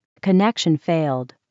crystal.connection.failed.mp3